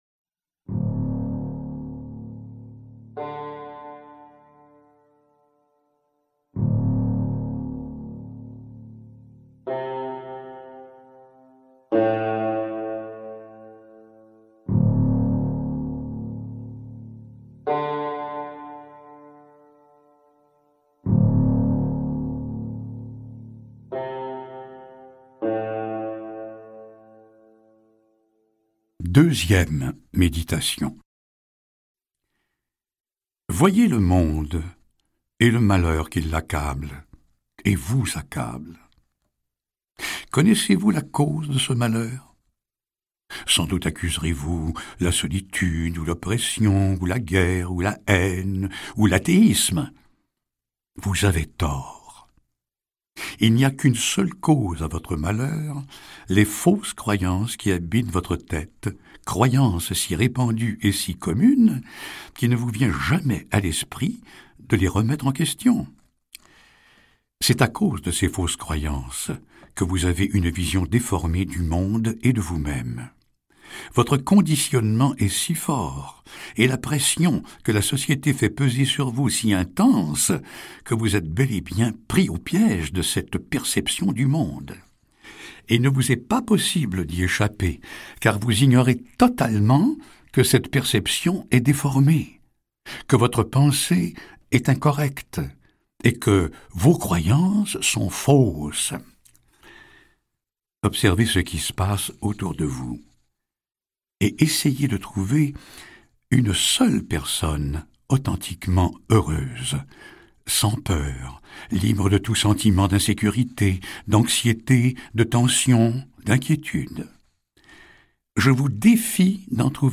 Extrait gratuit - Appel à l'amour de Anthony De Mello, Gérard Poirier